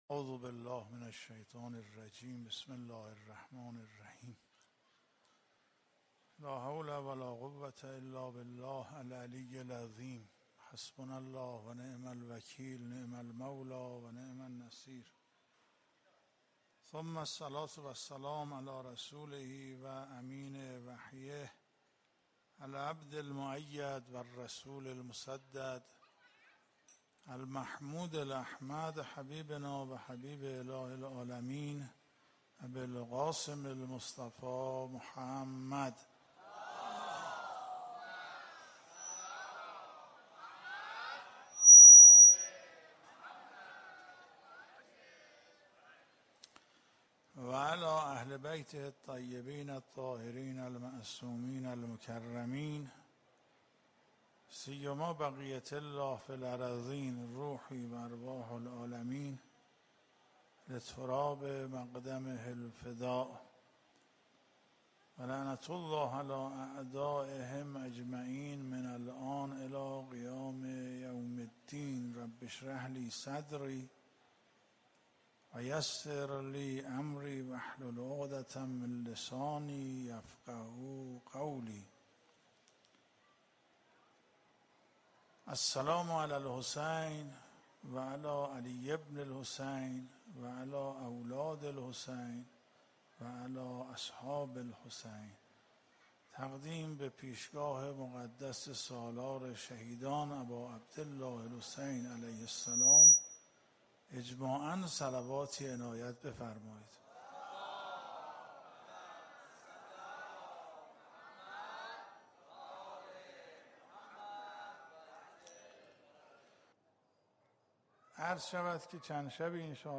سخنرانی
شب اول محرم 1441